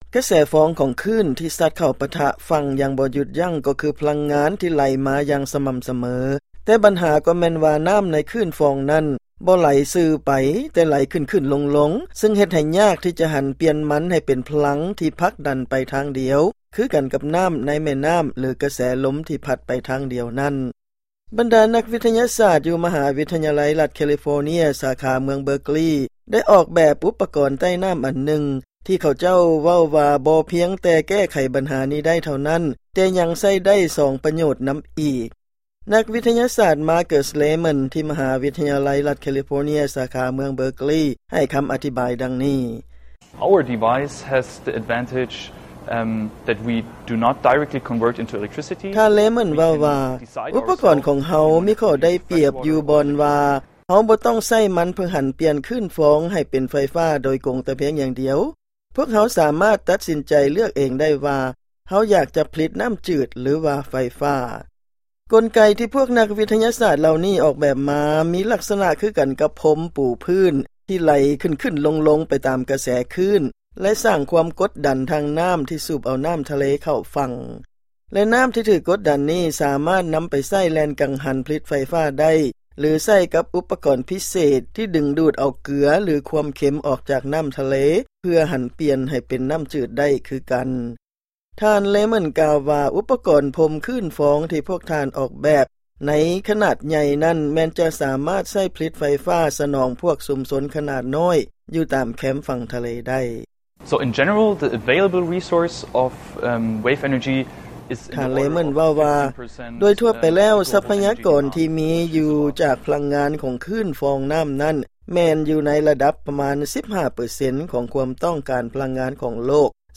ຟັງລາຍງານກ່ຽວກັບ ການໝຸນໃຊ້ພະລັງງານ ຈາກນ້ຳທະເລ